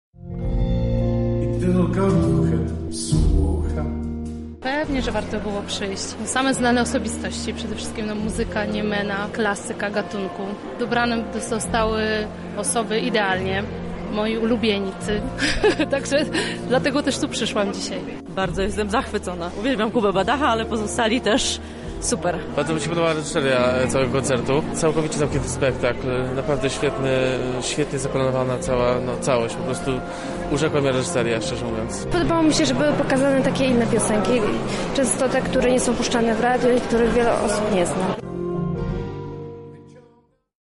Relacja z wydarzenia